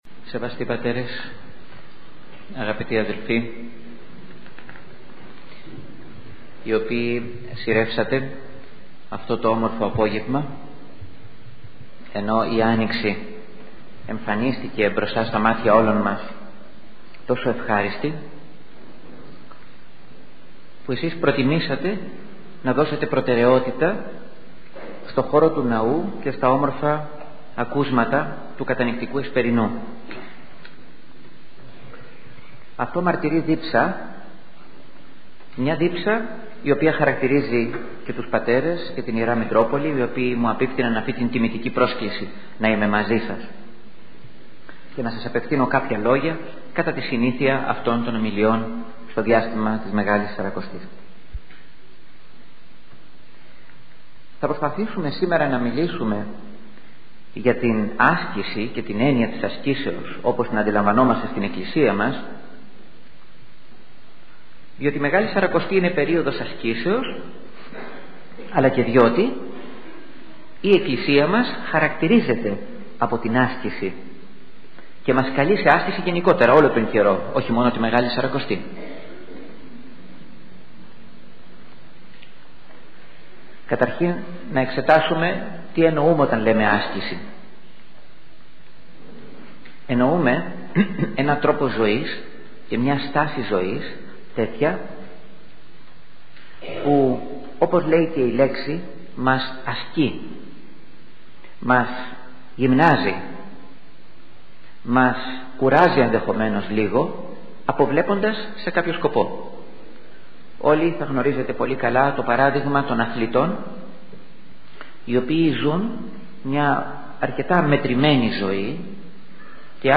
ηχογραφημένη ομιλία